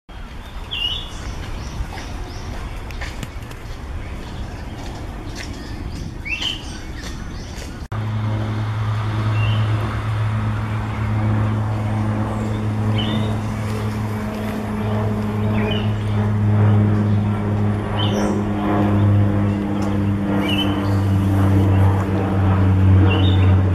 マミジロ　2016-05-02　IMG_4112
囀り　MVI_4085/86.mp3　00:23